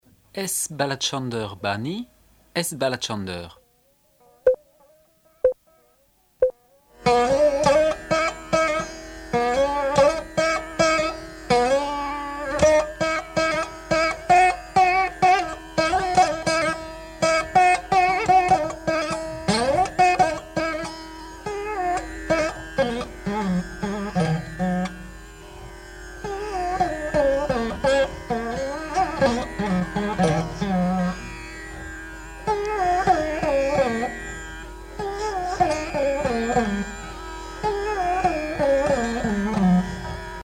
Musique carnatique
Pièce musicale inédite